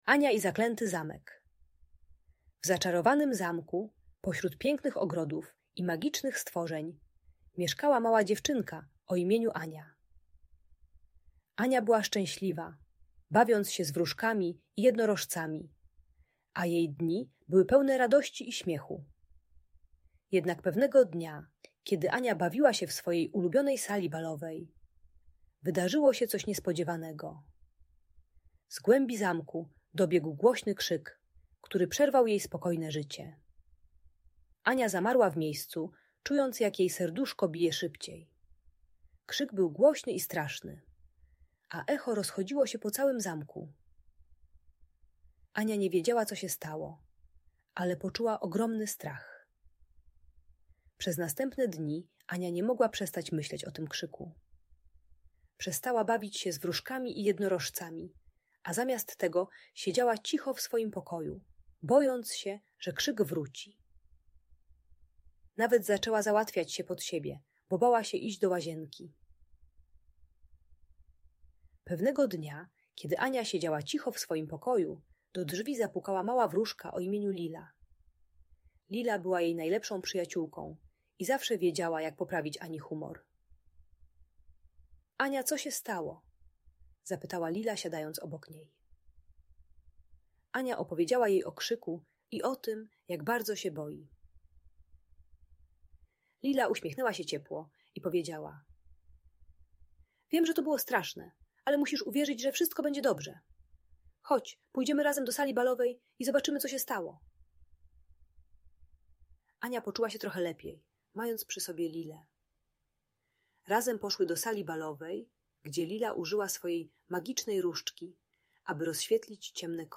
Ania i Zaklęty Zamek: Magiczna Opowieść o Odwadze i Przyjaźni - Audiobajka